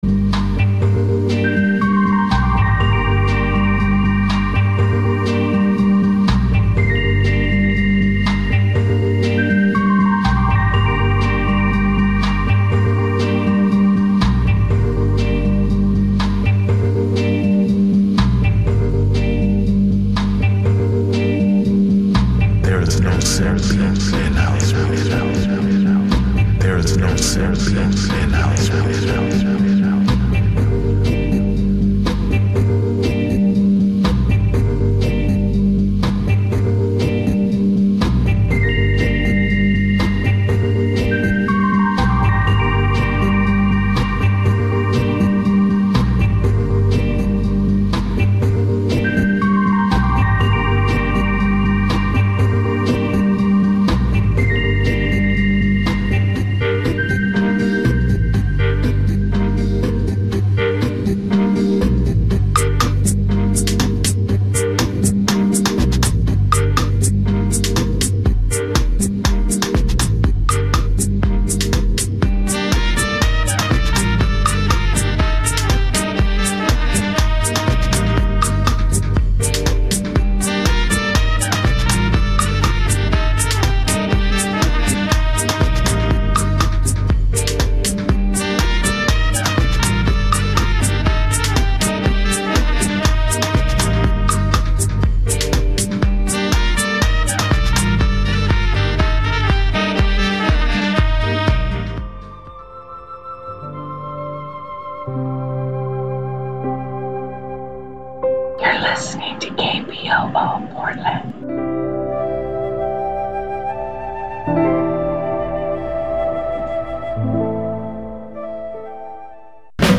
Daily Hip Hop Talk Show